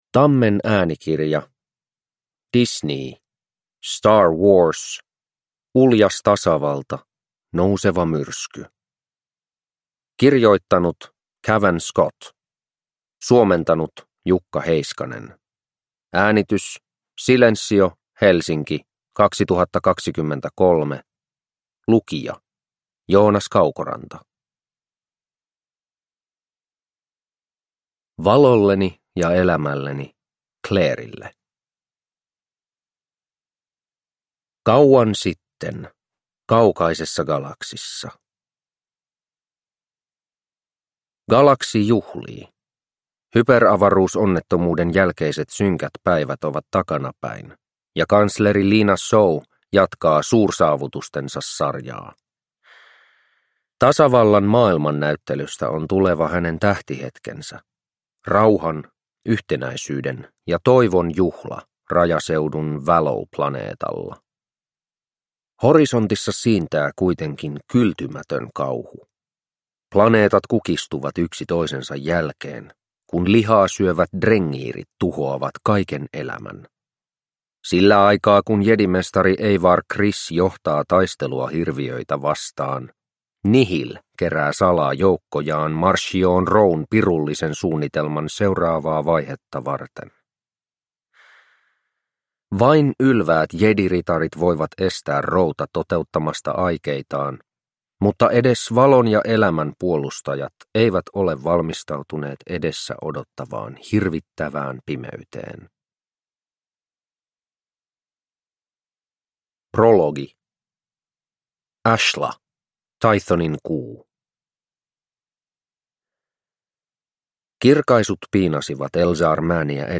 Star Wars Uljas tasavalta. Nouseva myrsky (ljudbok) av Cavan Scott